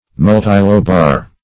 Search Result for " multilobar" : The Collaborative International Dictionary of English v.0.48: Multilobar \Mul`ti*lo"bar\, a. [Multi- + lobar.] Consisting of, or having, many lobes.